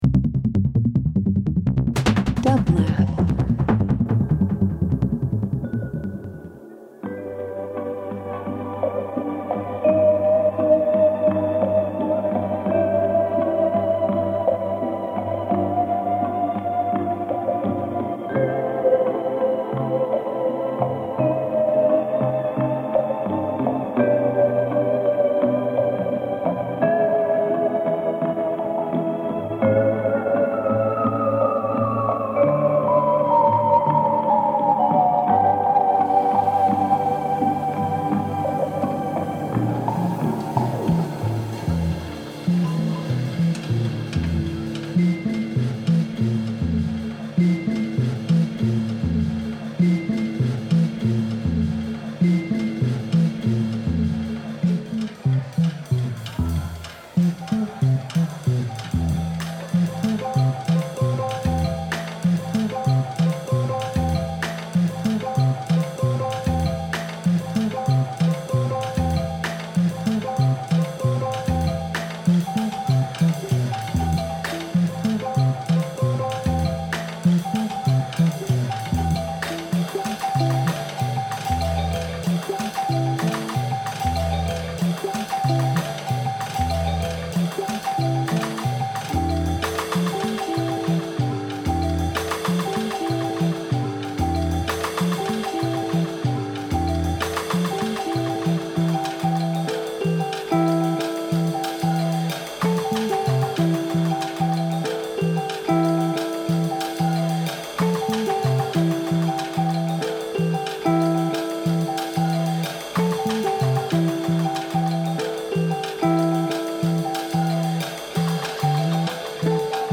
Alternative Funk/Soul Jazz